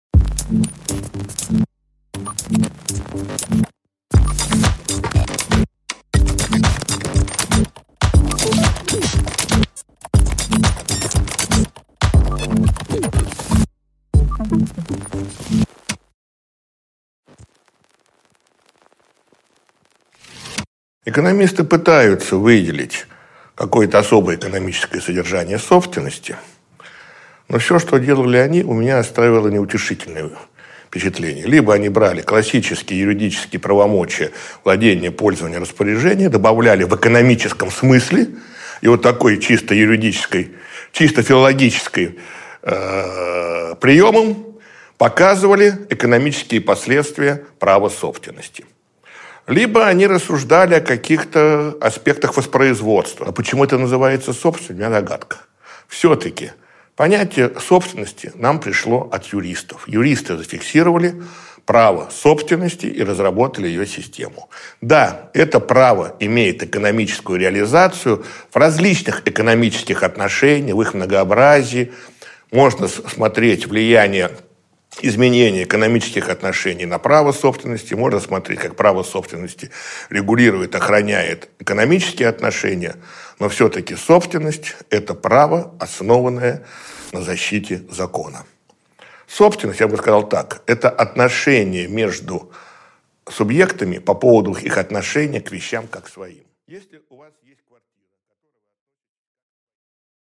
Аудиокнига Метаморфозы своего и чужого | Библиотека аудиокниг